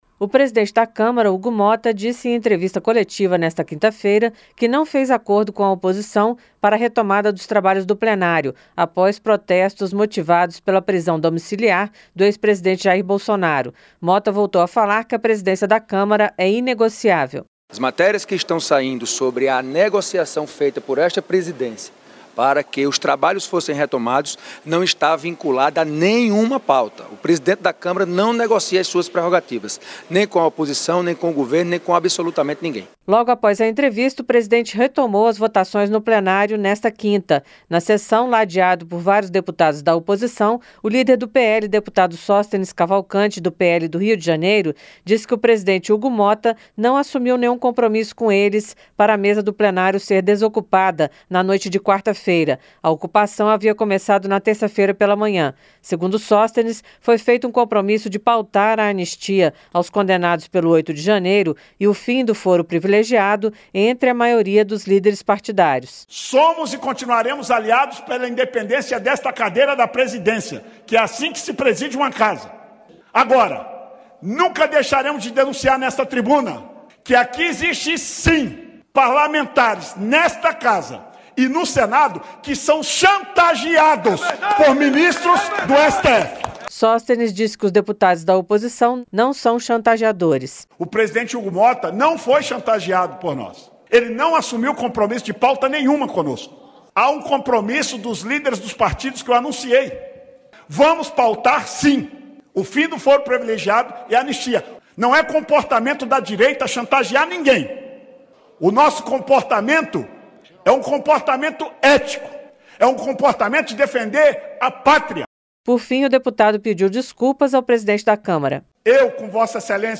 Motta concede entrevista
• Áudio da matéria